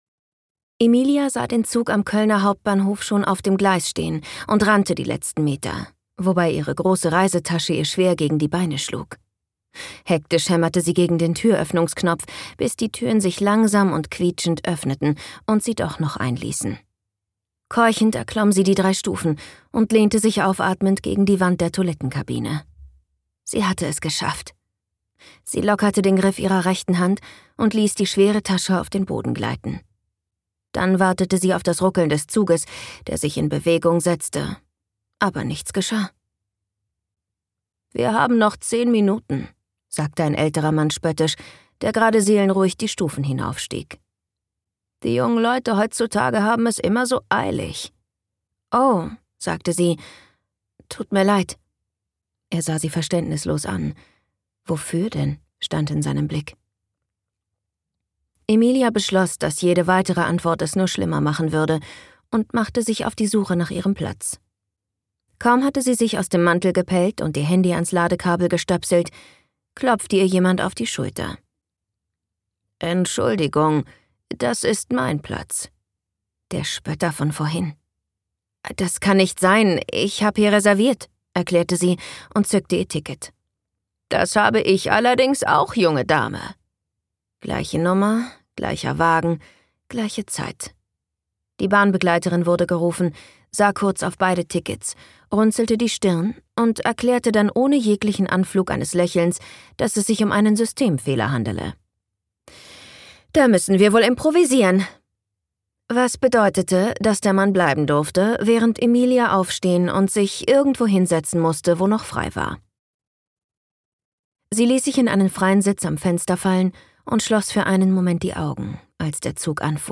Books & Coffee – An Wunder muss man glauben - Ella Lindberg | argon hörbuch